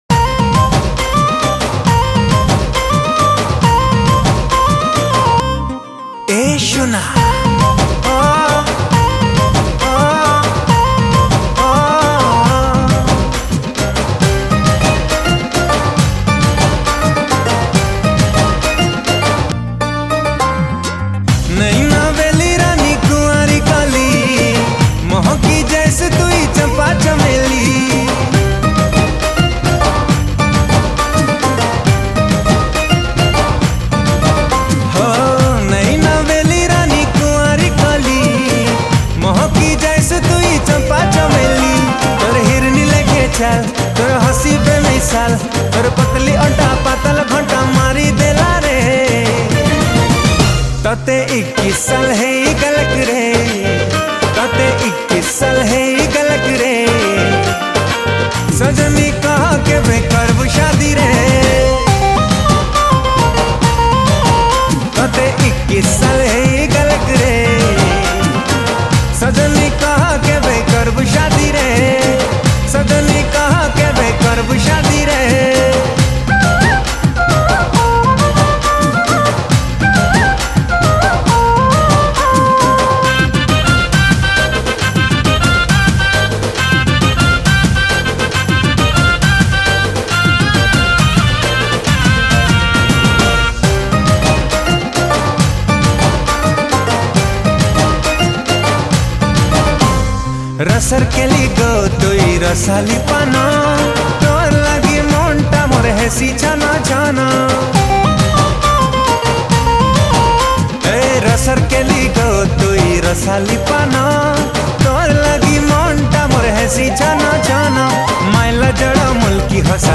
Keyboard
Drums